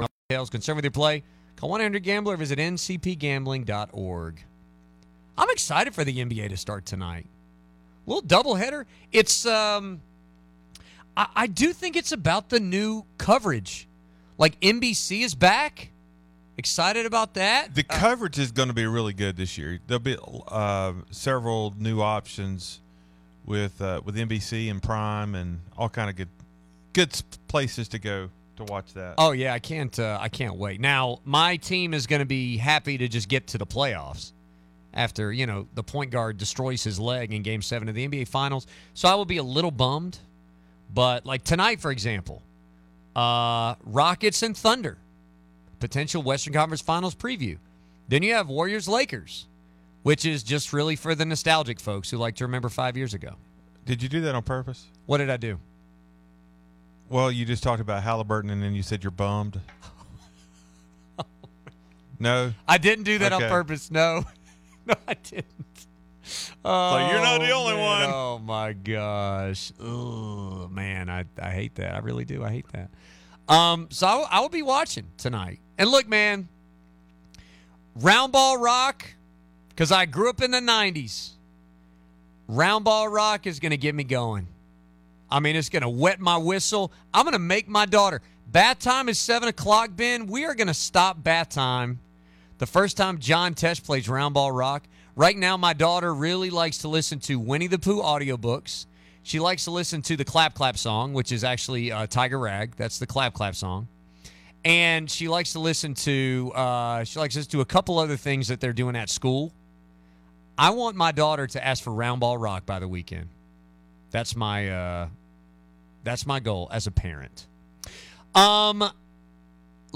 Out of Bounds is a fast paced show that covers all things sports both locally and nationally.
Sports News